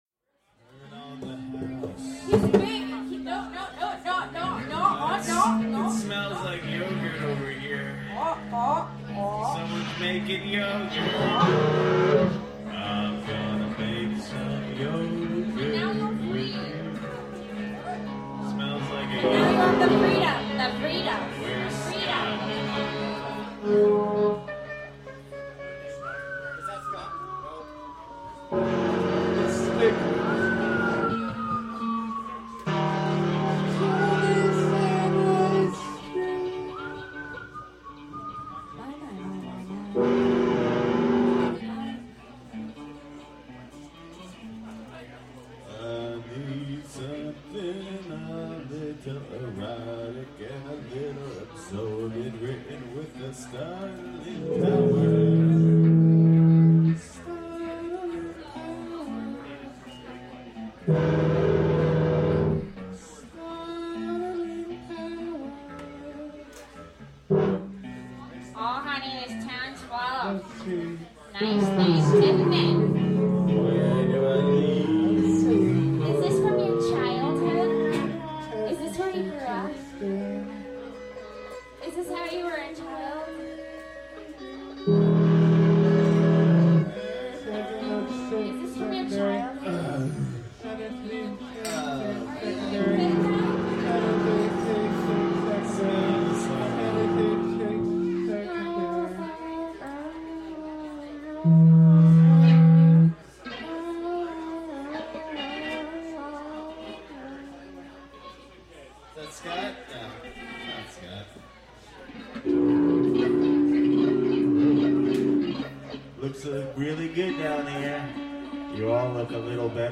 noise/experimental band